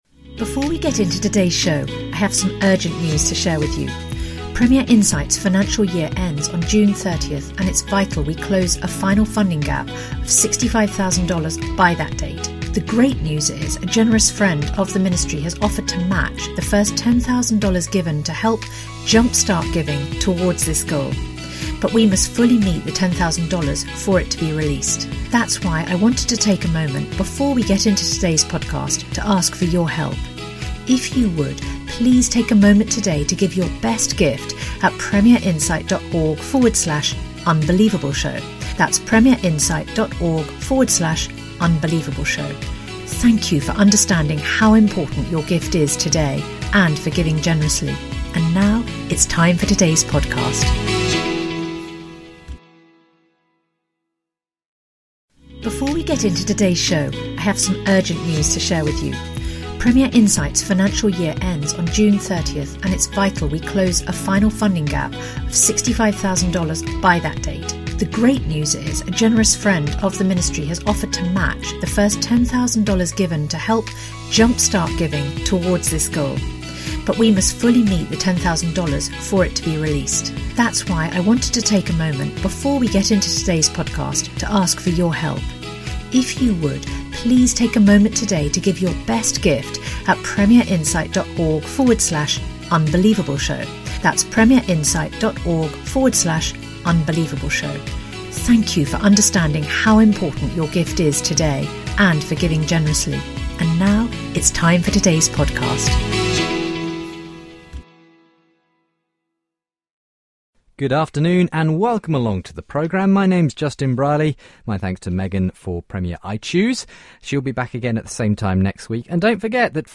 a discussion between Christians and non-christians